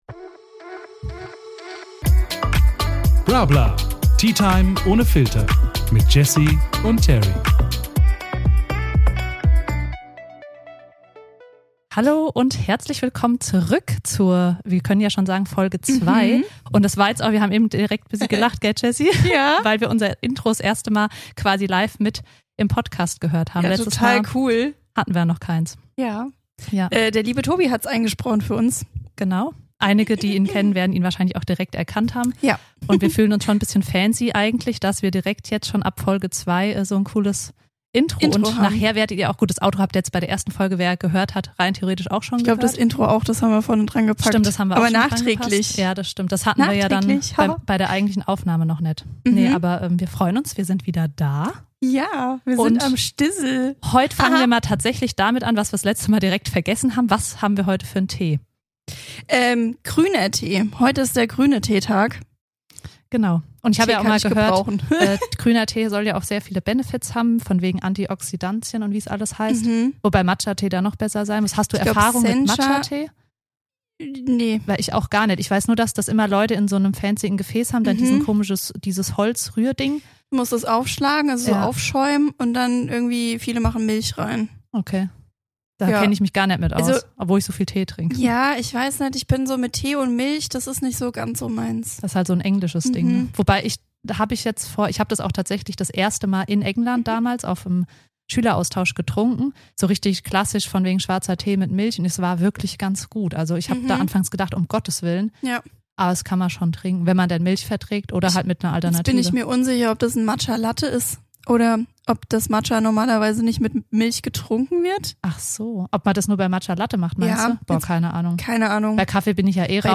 In diesem Podcast sprechen die Autorinnen offen und ehrlich über ihre persönlichen Erfahrungen mit Bodyshaming und dem Genderspektrum.